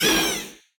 LoudWailStart.wav